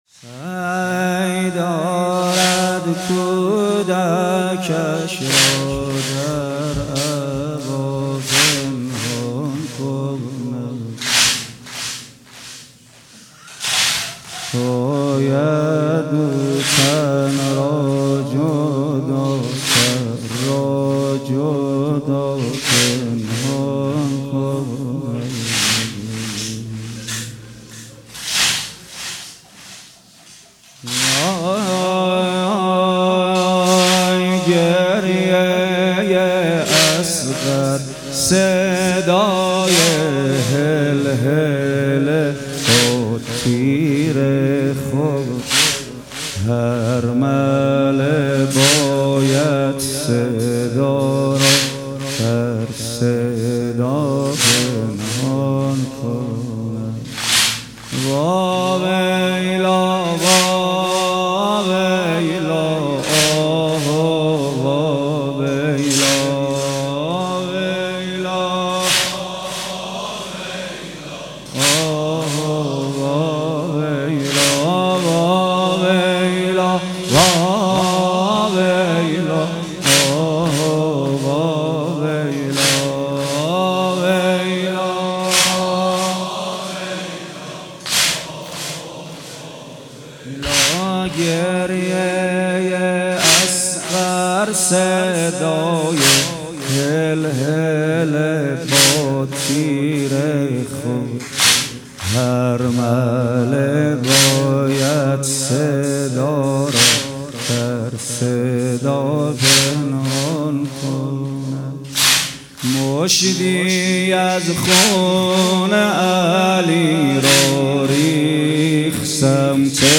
شب هفتم در امامزاده‌ صالح‌ تجریش
بخش ۱ : روضه